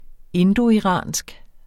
Udtale [ ˈendoiˌʁɑˀnsg ]